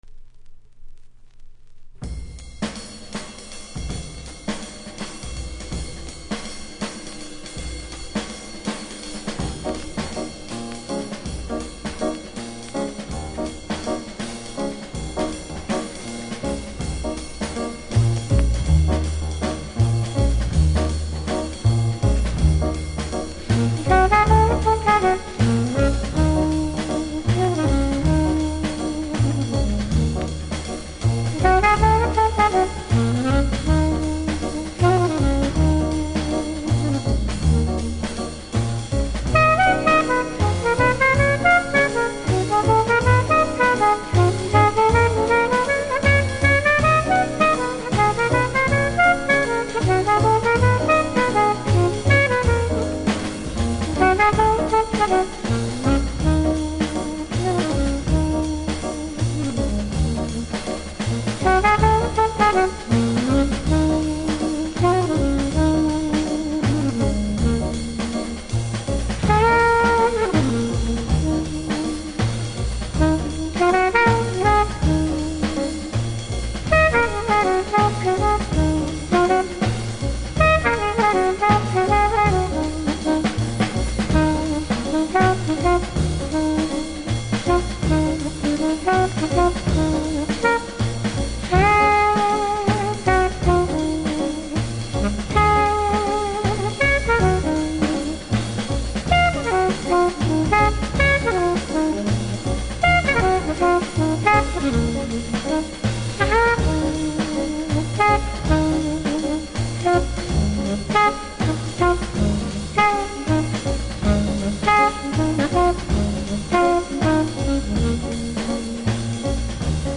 INSTRUMENTAL
途中から入るベースラインがサウンド・システムで聴くとたまりません！
キズは多めですがノイズはそれほど音に影響無くプレイは問題無いレベル。